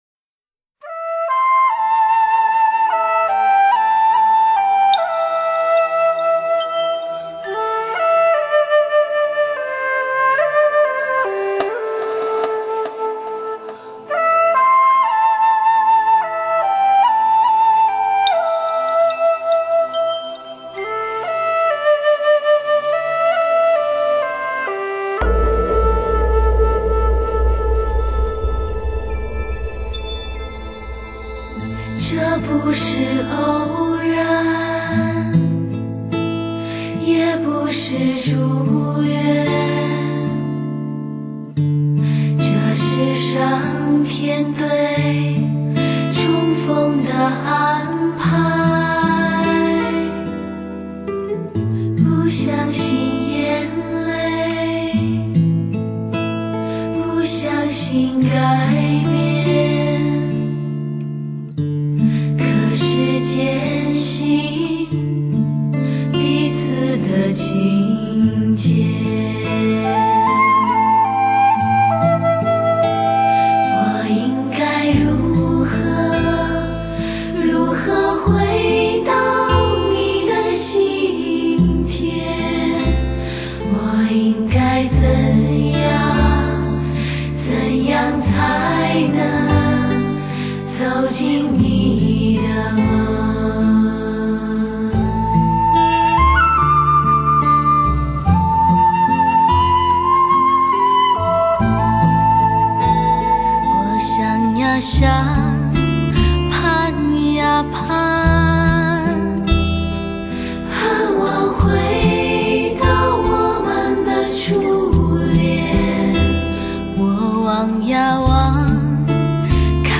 永恒的魅力 绝妙的HI-FI音乐欣赏
超强女声组合 情爱如火 音色似水
月光一样的清纯女声 给你心灵最柔软的抚摸